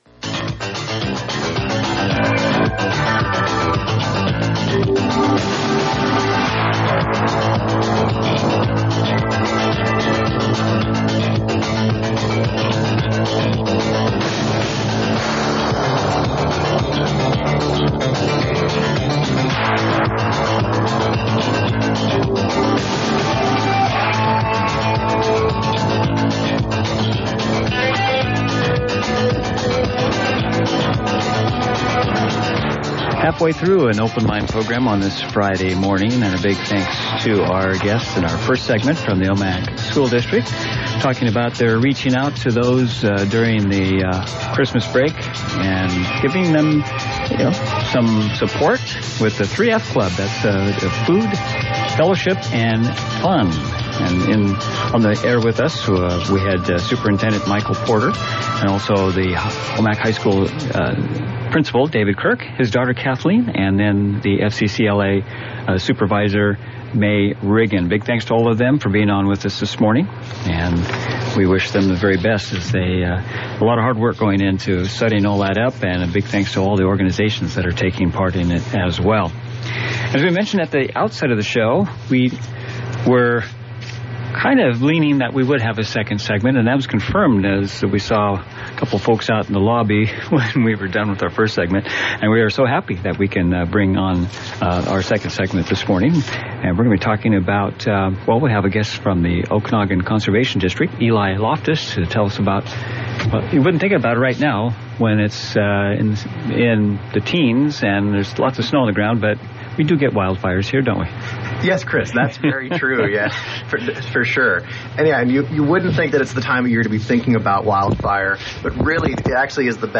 You can listen in to the radio at 92.7 to hear Okanogan CD staff discussing programs and services that are available throughout the year.